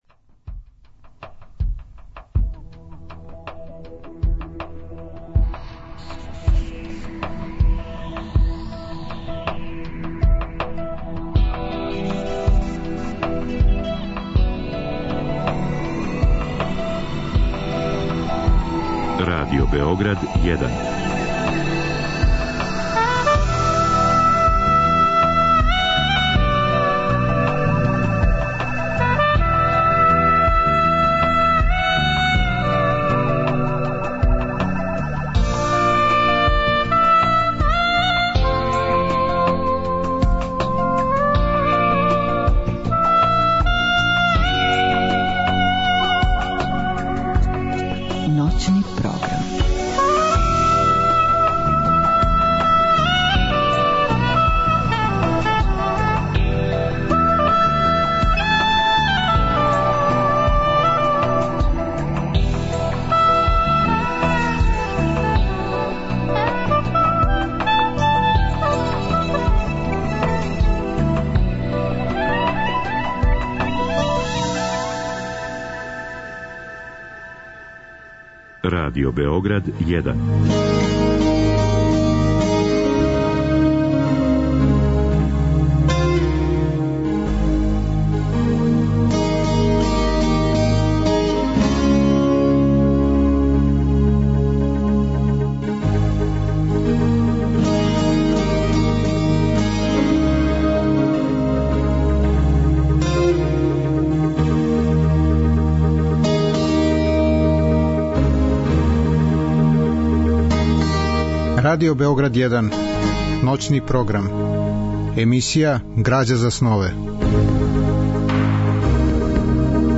Емисија ноћног програма под називом ГРАЂА ЗА СНОВЕ бави се питањима од значаја за уметност и стваралаштво. Гости су људи из различитих професија, они који су и сами ствараоци, и блиска им је сфера духа и естетике. Разговор и добра музика требало би да кроз ову емисију и сами постану грађа за снове.